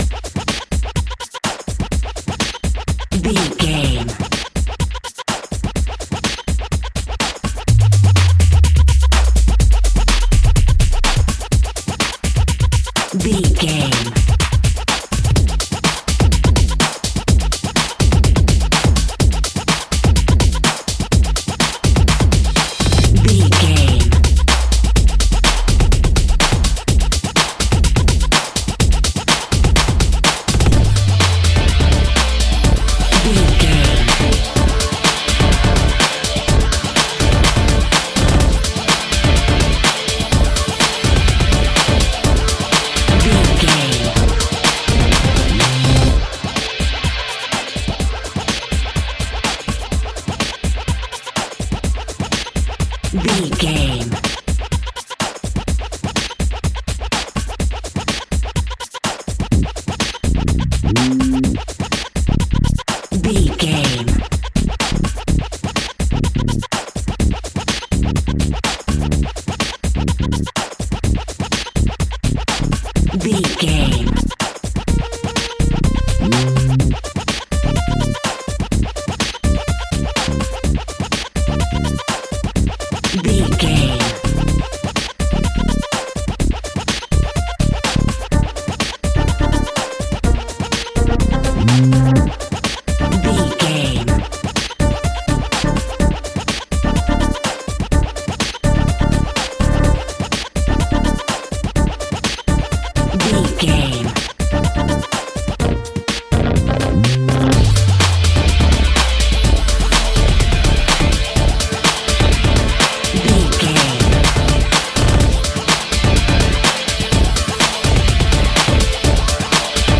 Fast paced
Aeolian/Minor
Fast
aggressive
dark
driving
energetic
drum machine
synthesiser
synth bass
synth lead